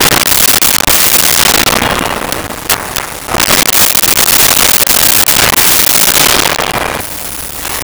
Doors Jail Open Close 01
Doors Jail Open Close 01.wav